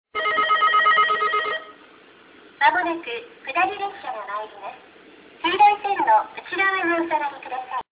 下り列車接近放送　女声
↑ミスで、ベルが途切れてます   放送は、筑豊・筑後型でした。
突然音声が流れますので、音量にご注意下さい。